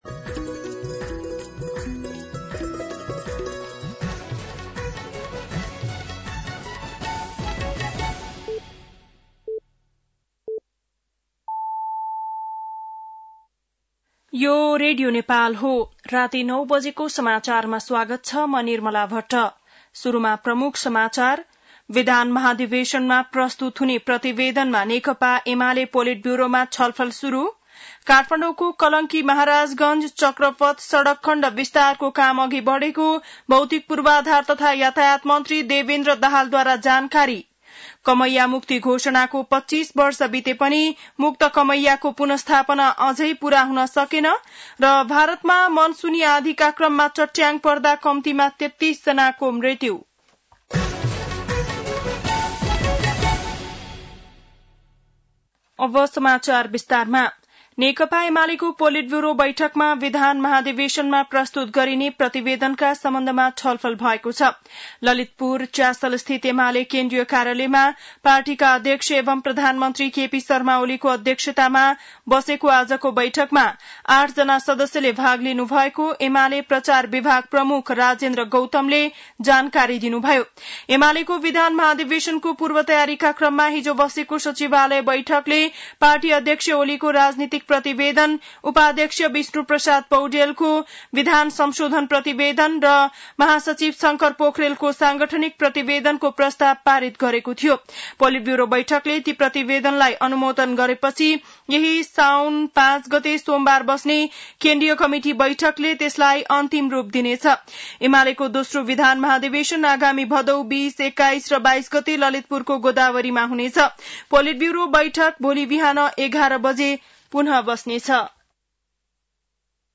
बेलुकी ९ बजेको नेपाली समाचार : २ साउन , २०८२